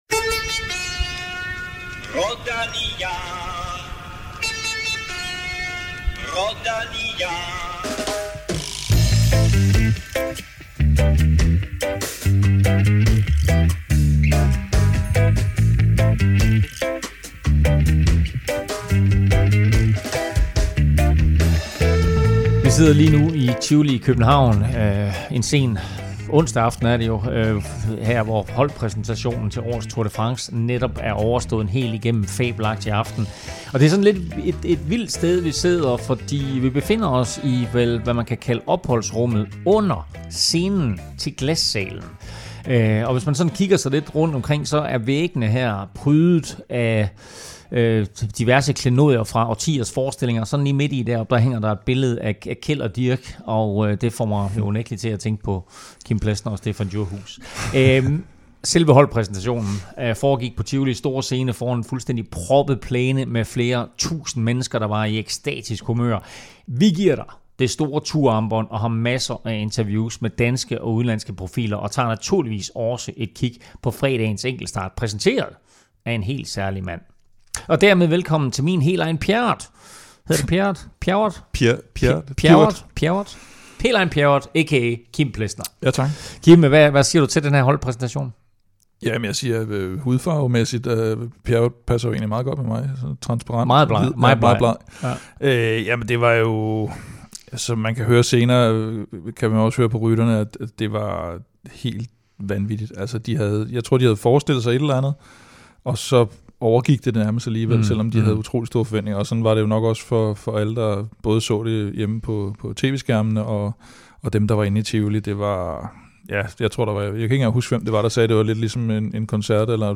Den store Tour-optakt optaget i forbindelse med holdpræsentationen i Tivoli. Vi taler med en lang perlerække af danskere og favoritter som Mads P, Mathieu van der Poel, Jonas Vingegaard og Bauke Mollema.